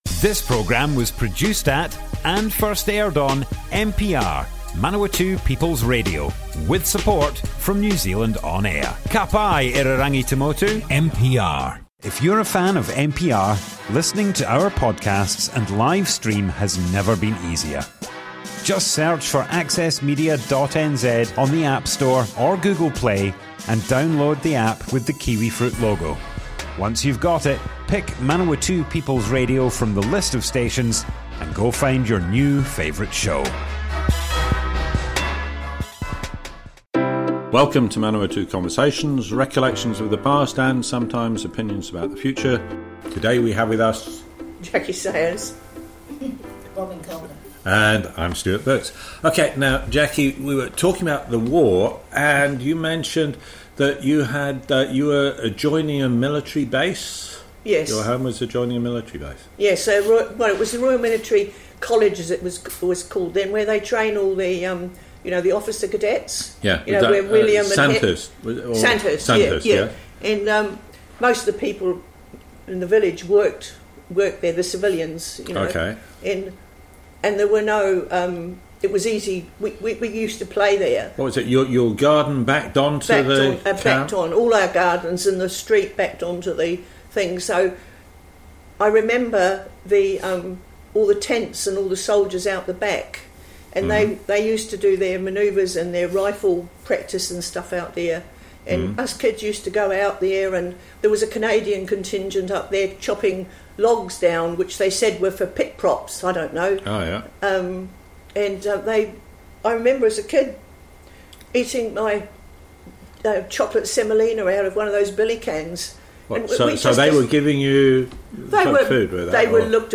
Manawatu Conversations More Info → Description Broadcast on Manawatu People's Radio, 3 August 2021.
oral history